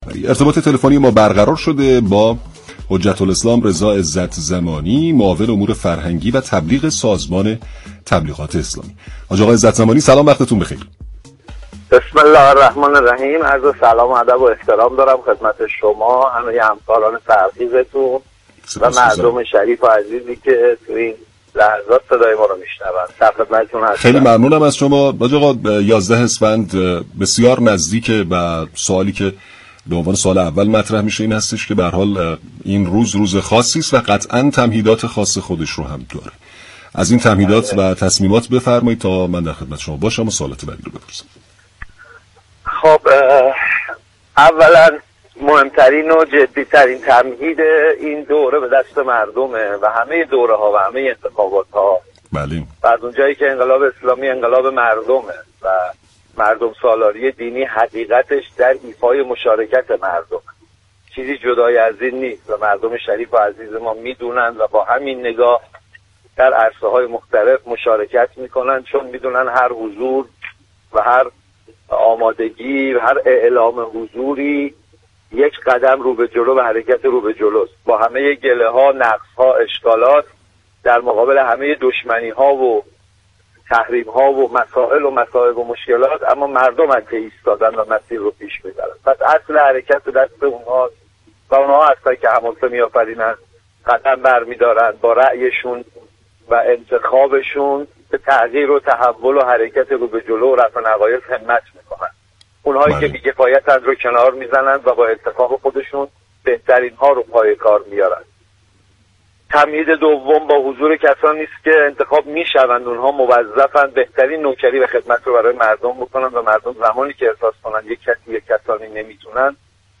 به گزارش پایگاه اطلاع رسانی رادیو تهران، حجت‌الاسلام رضا عزت‌زمانی معاون امور فرهنگی و تبلیغ سازمان تبلیغات اسلامی در گفت و گو با «پارك شهر» اظهار داشت: مهمترین اتفاق این دوره از انتخابات، به دست مردم رقم می‌خورد.